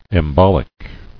[em·bol·ic]